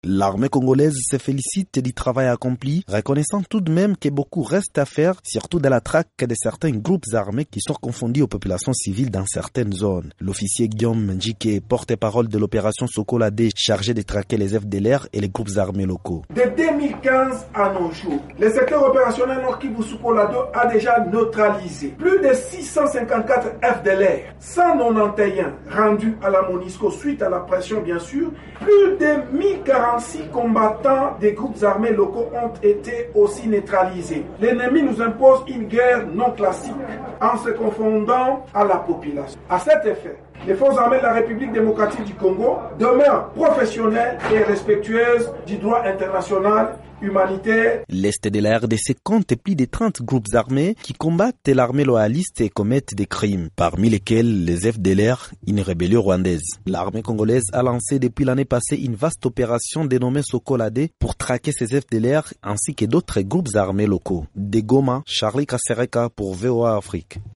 Correspondance